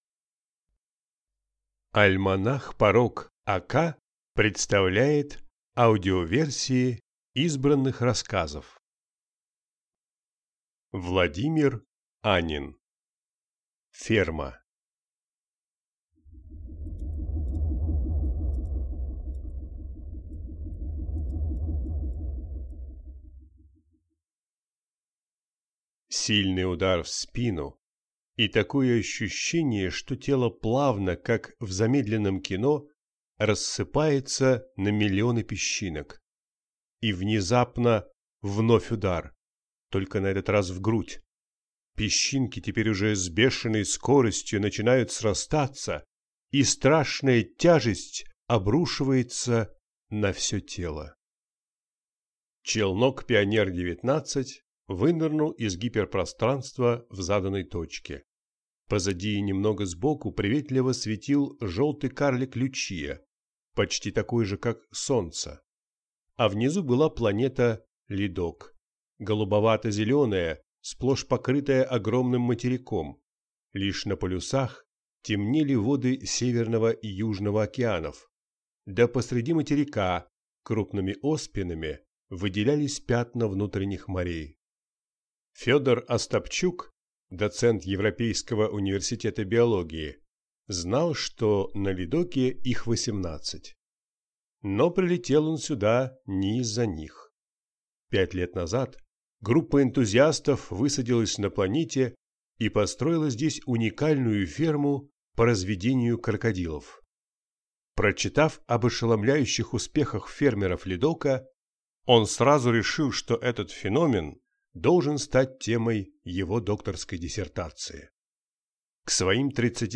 Аудиокнига Ферма | Библиотека аудиокниг